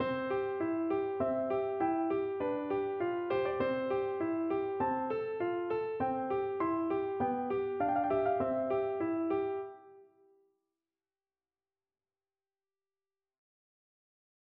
모차르트의 피아노 소나타 C장조, K. 545의 첫 마디는 제2전위와 제1전위 딸림7화음을 특징으로 한다.
모차르트 피아노 소나타 C장조, K. 545의 첫 마디 (오디오)